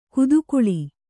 ♪ kudukuḷi